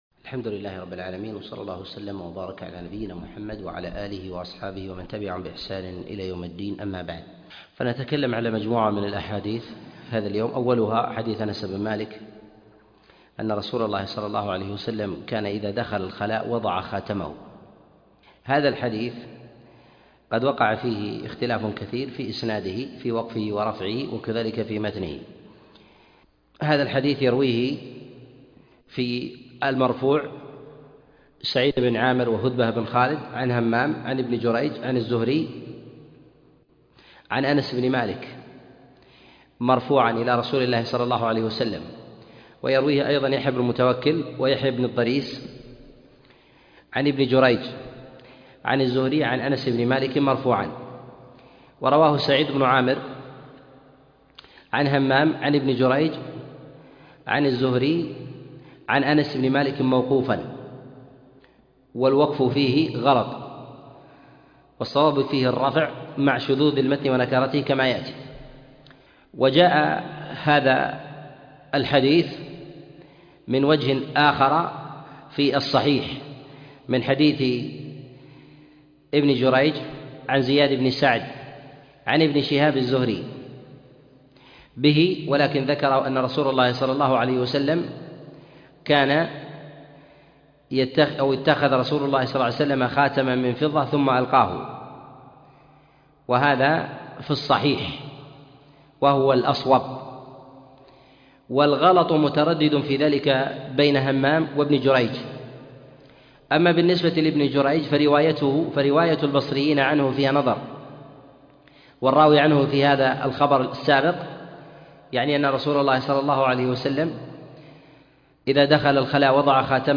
الأحاديث المعلة في الطهارة الدرس 7